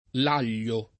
Laglio [ l # l’l’o ]